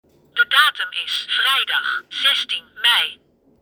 Datum-Iris-sprekend-horloge.mp3